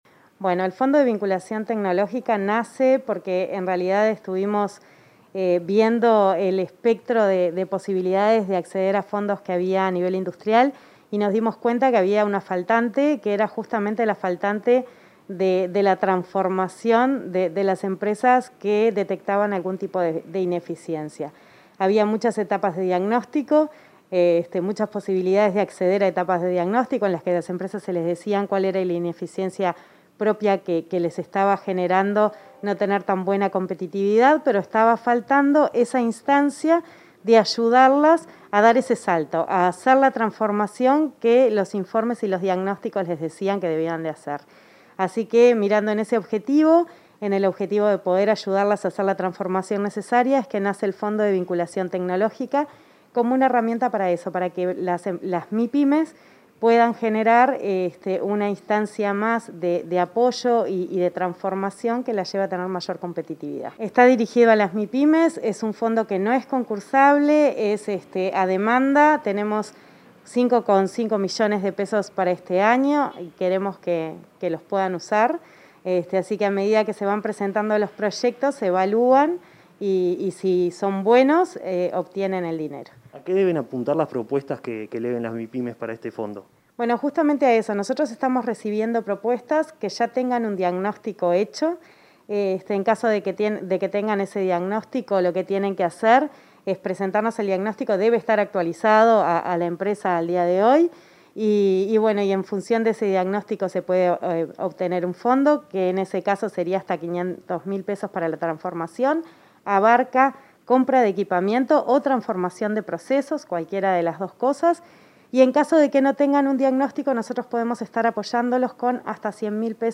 Entrevista a la directora nacional de Industrias, Susana Pecoy